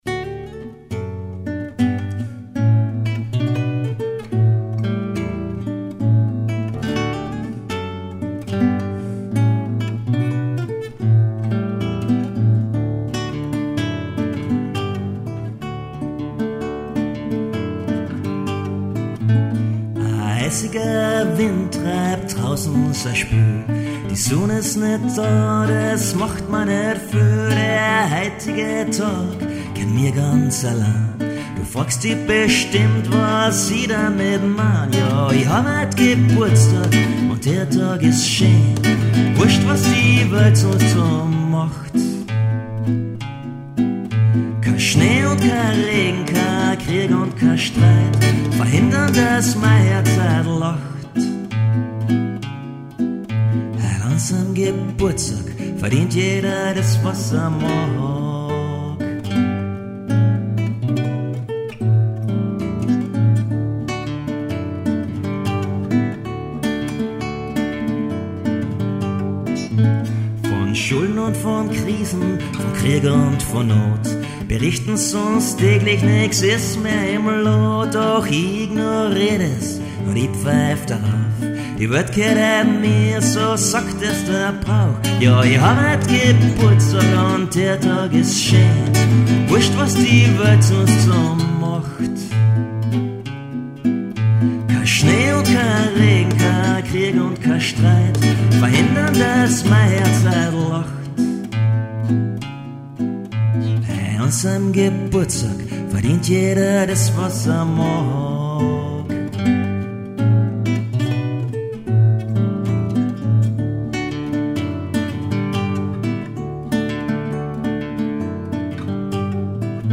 vocals, guitar
recording at his workroom studio in Vienna